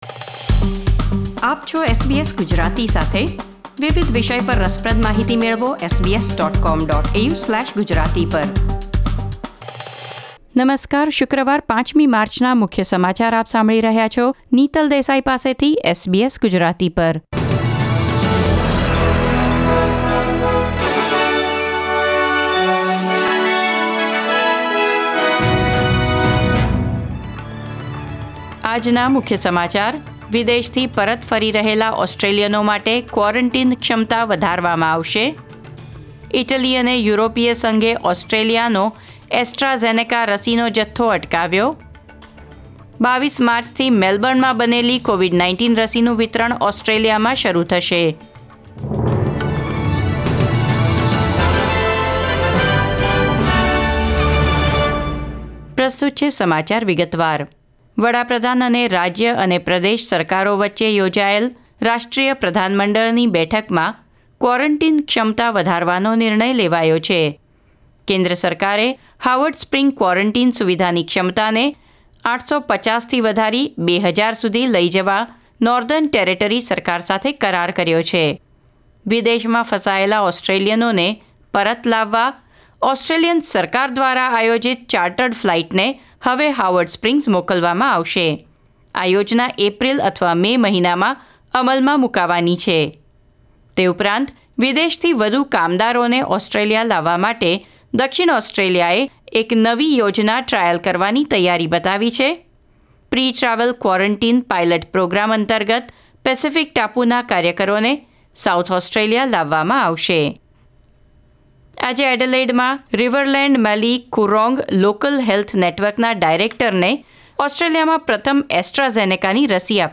SBS Gujarati News Bulletin 5 March 2021